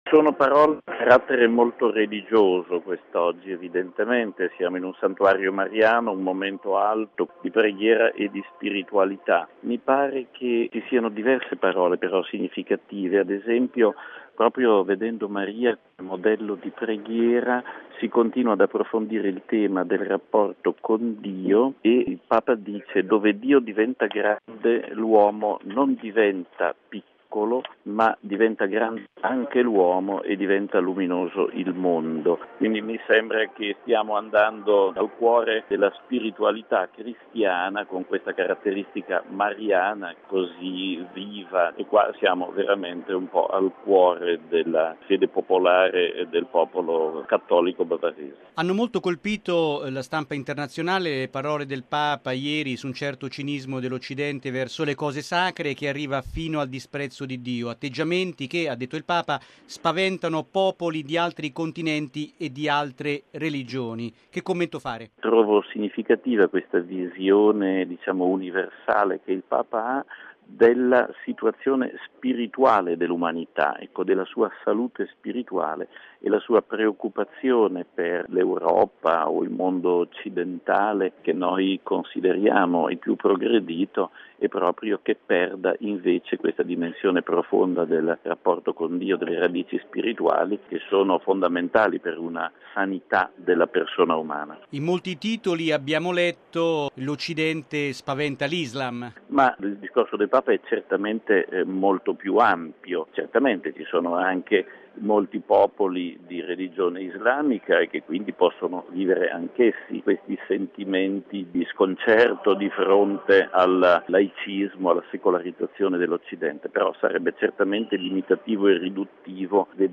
Sulle parole del Papa oggi ad Altötting e su queste prime giornate del viaggio in Baviera ascoltiamo il commento del nostro direttore generale padre Federico Lombardi